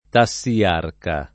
vai all'elenco alfabetico delle voci ingrandisci il carattere 100% rimpicciolisci il carattere stampa invia tramite posta elettronica codividi su Facebook tassiarco [ ta SS i- # rko ] o tassiarca [ ta SS i- # rka ] s. m. (stor.); pl.